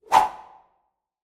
Sword Swish 1.wav